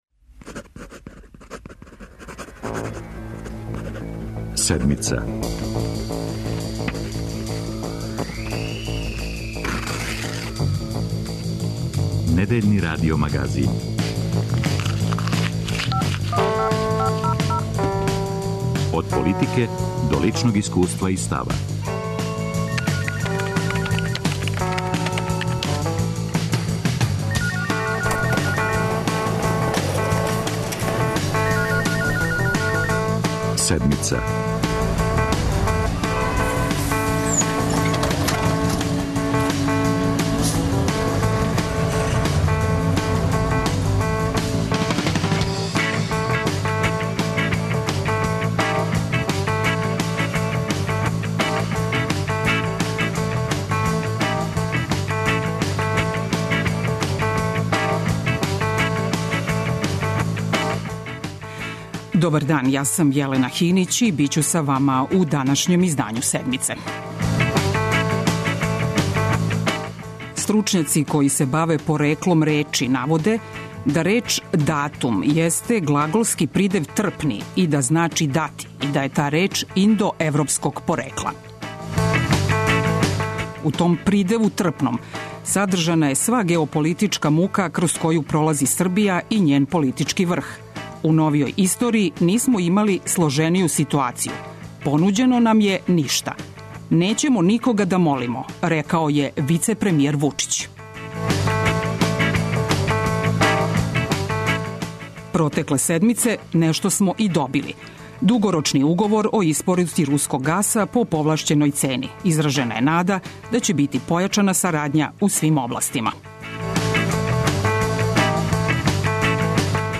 Гост емисије је директор Србијагаса и потпредседник Социјалистичке Партије Србије Душан Бајатовић. Разговараћемо о енергетици у Србији, која је осим економске и политичке данас и социјална категорија.